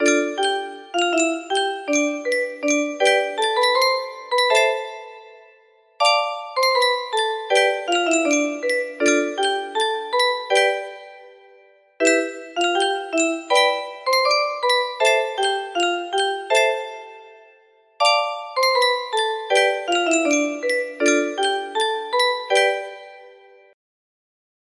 Clone of 애국가 기본 music box melody
Grand Illusions 30 (F scale)